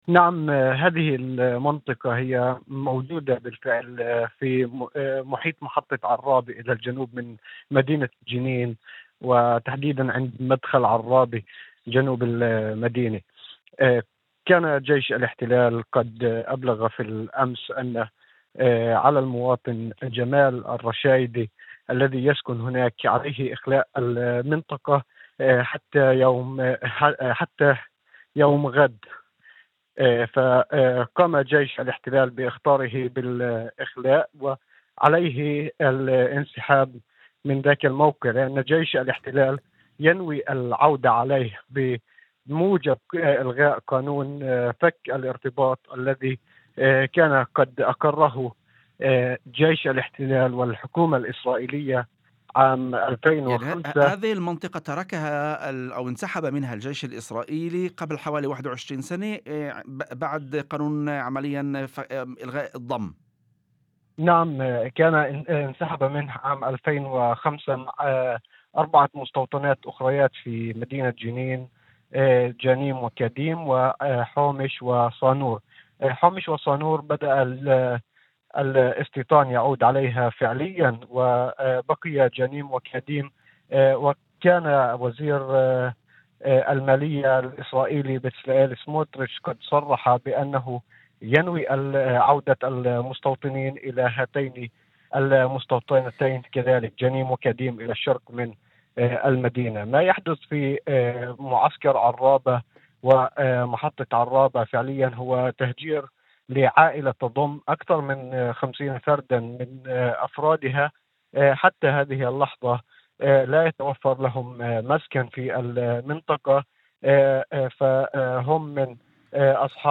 مداخلة هاتفية